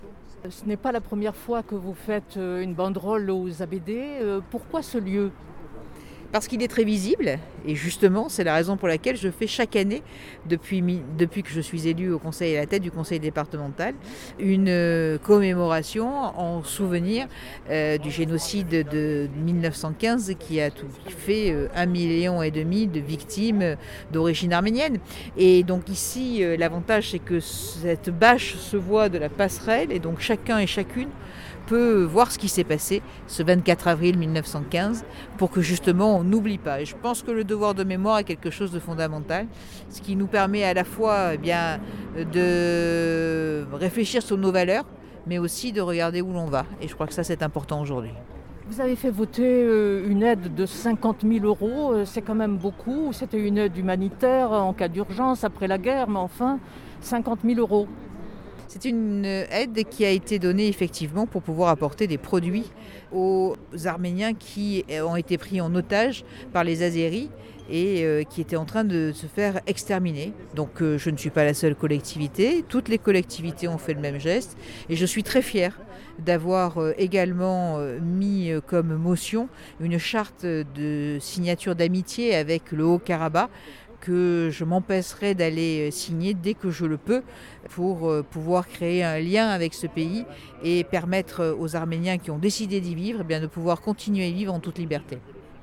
Entretiens